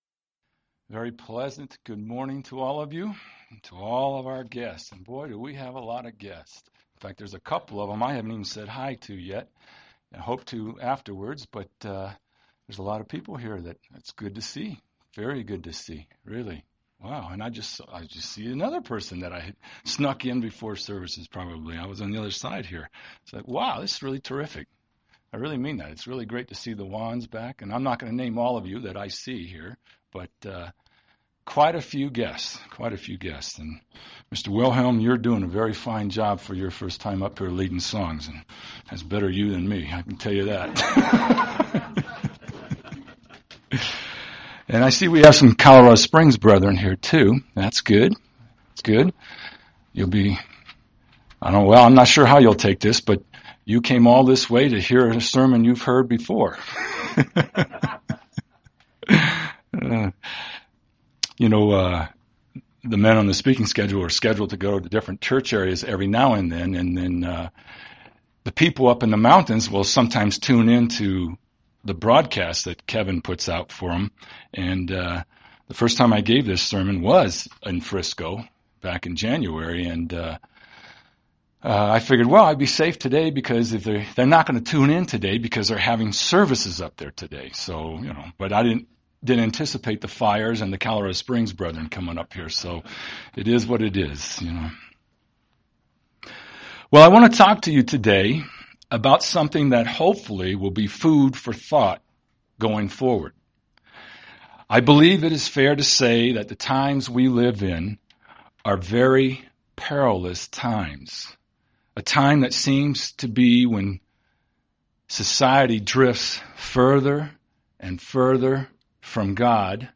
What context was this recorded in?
Given in Denver, CO